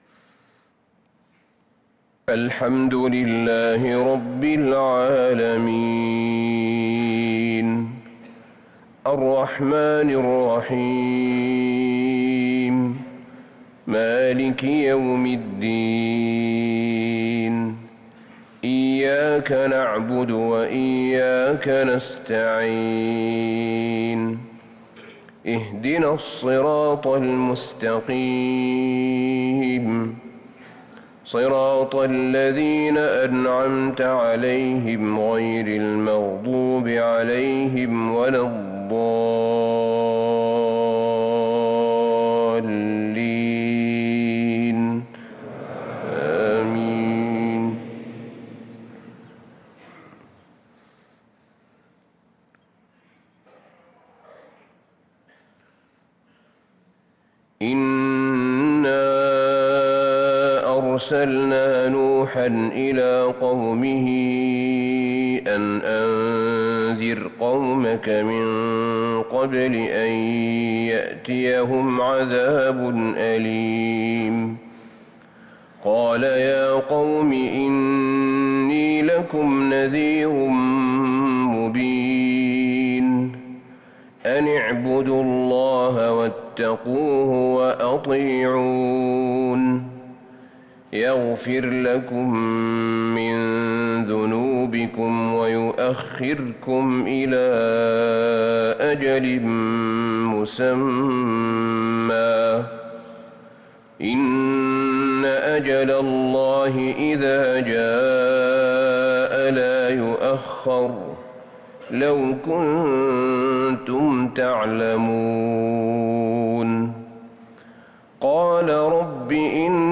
صلاة الفجر للقارئ أحمد بن طالب حميد 24 ذو الحجة 1443 هـ
تِلَاوَات الْحَرَمَيْن .